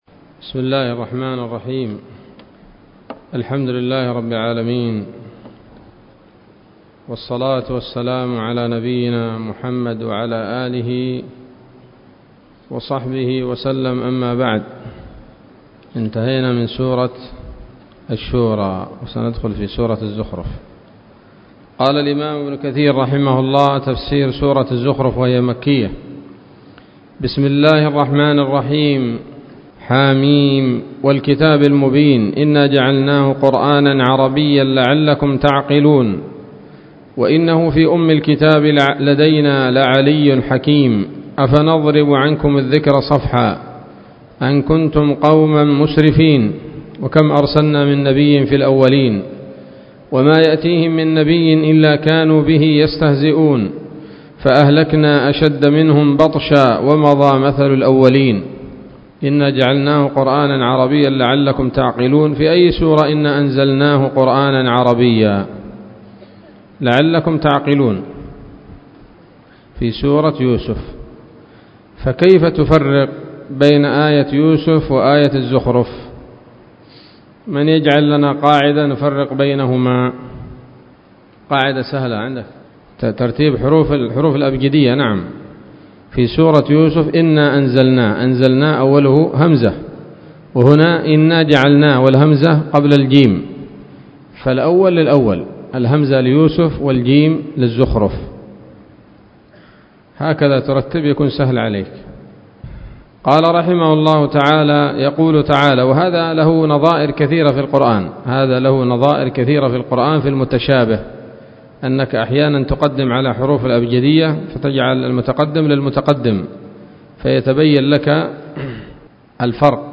الدرس الأول من سورة الزخرف من تفسير ابن كثير رحمه الله تعالى